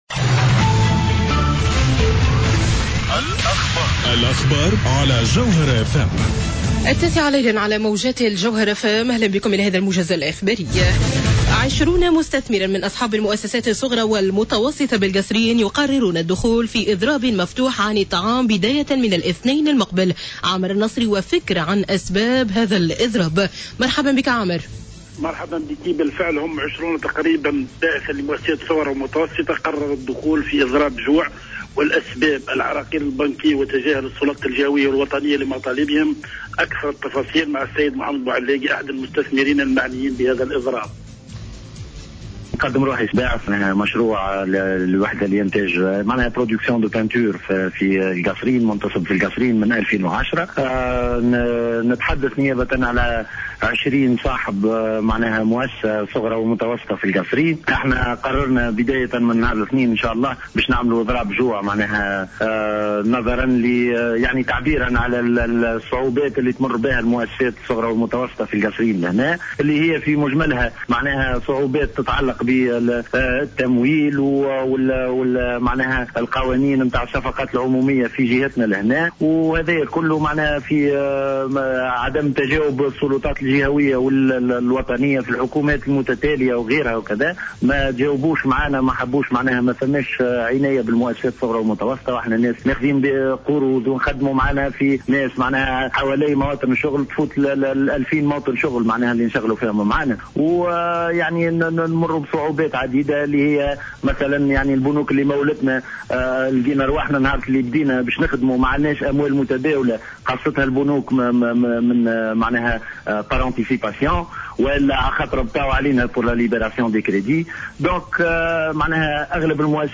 موجز الأخبار